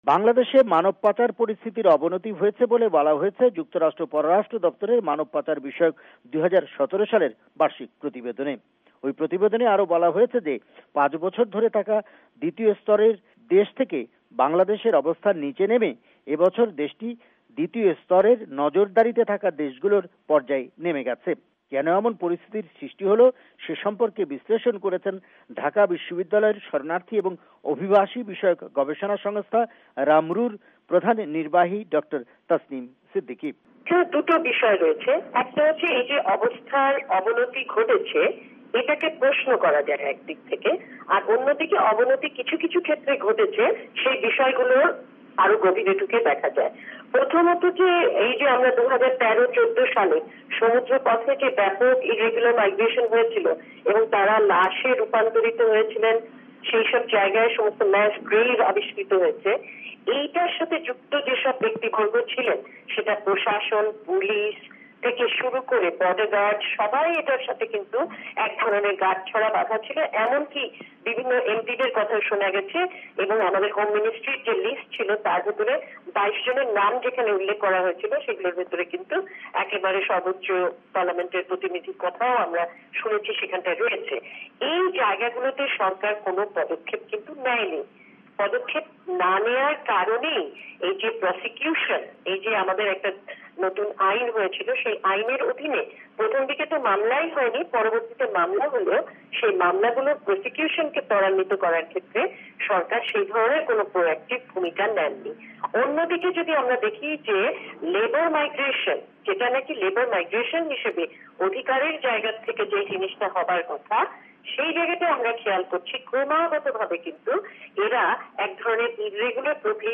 রিপোর্ট সাক্ষাৎকার